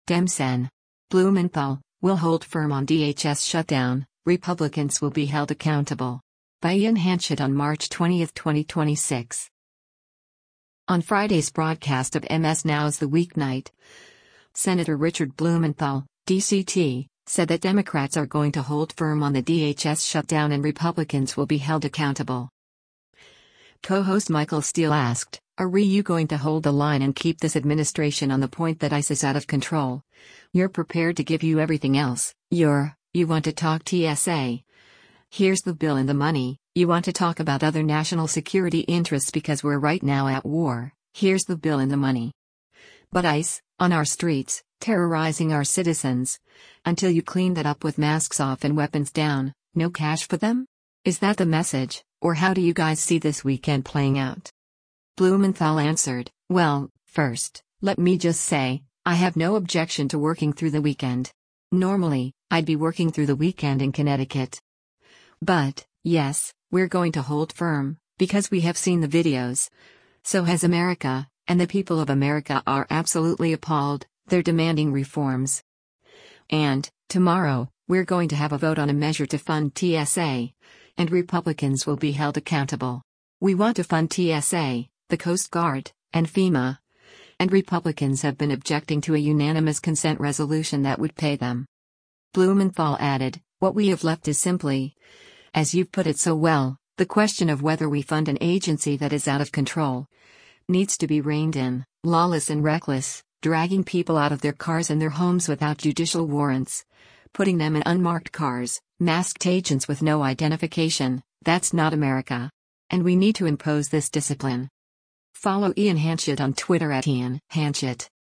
On Friday’s broadcast of MS NOW’s “The Weeknight,” Sen. Richard Blumenthal (D-CT) said that Democrats are “going to hold firm” on the DHS shutdown “and Republicans will be held accountable.”